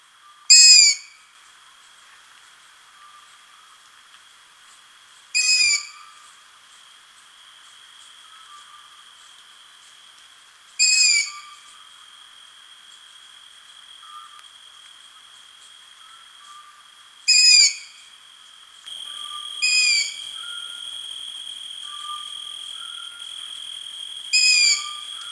Asio otus - Long eared owl - Gufo comune
- POSITION: Patanella copse near Orbetello lagoon, LAT.N 42°27'/LONG.E 11°13' - ALTITUDE: 0 m. - VOCALIZATION TYPE: calls of recently fledged youngs. - SEX/AGE: fledged young of unknown sex. - COMMENT: The young birds call almost continuously (background: Scops owl and crickets).
call4.mp3